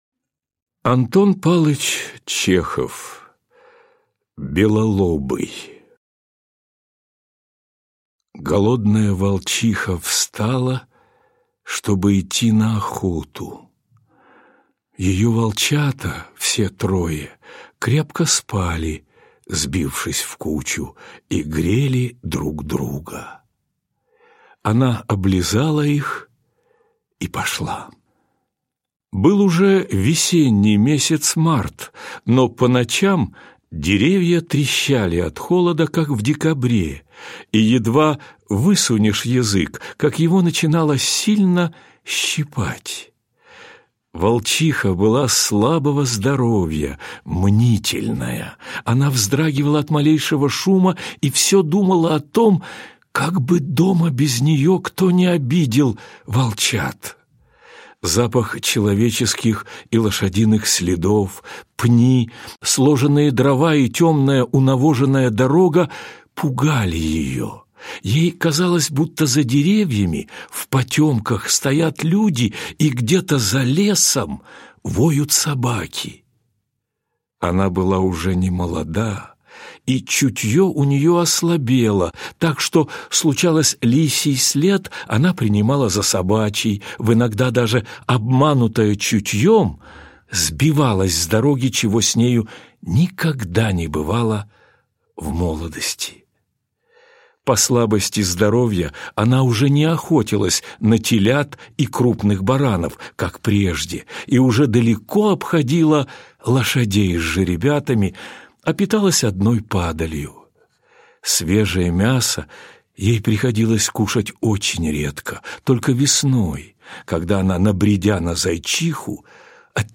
Слушать онлайн аудиокнигу "Белолобый":